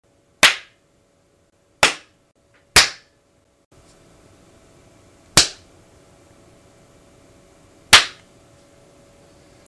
Download Slap sound effect for free.
Slap